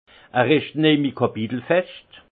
Haut Rhin
Ville Prononciation 68
Munster